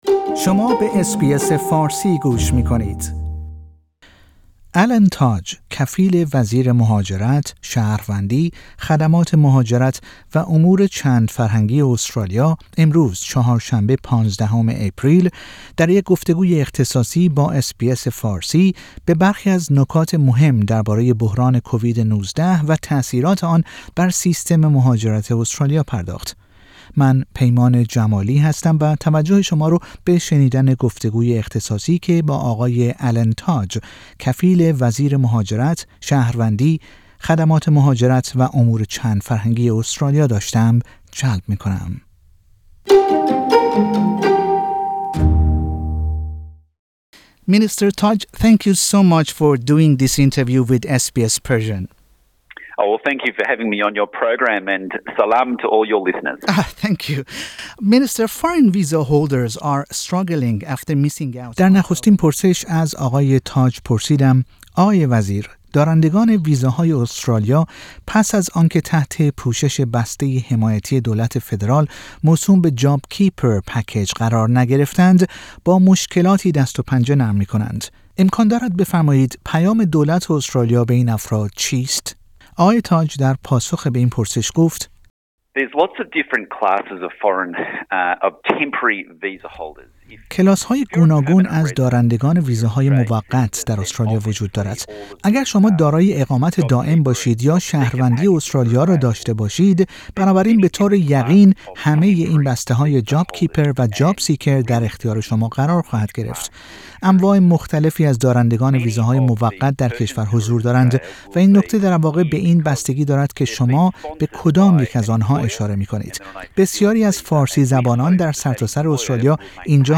گفتگوی اختصاصی کفیل وزیر مهاجرت استرالیا با اس بی اس فارسی